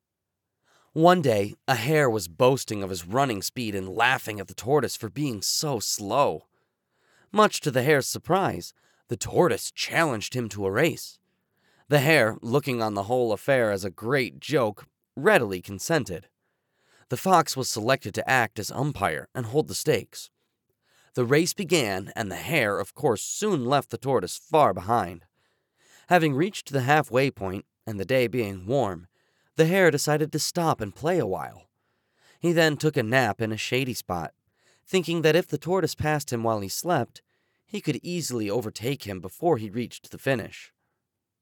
Male
Yng Adult (18-29), Adult (30-50)
Narration
Studio Quality Sample